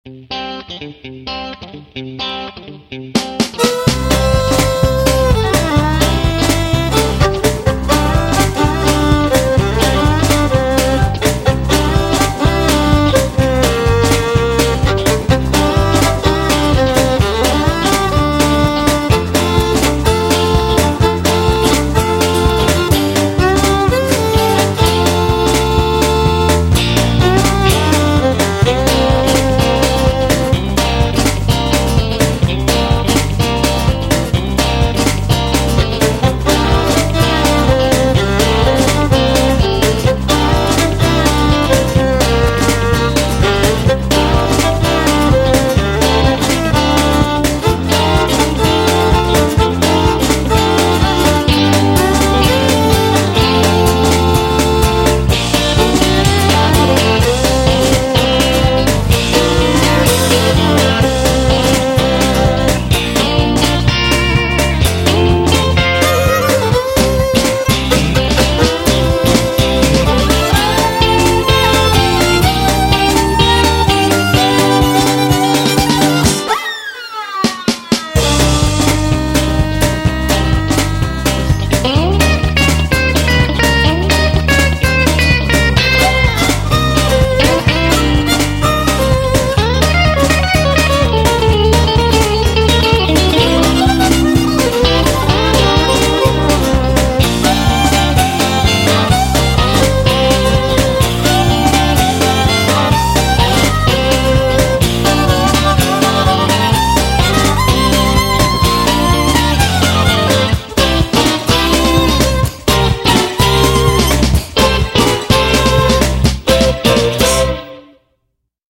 片头音乐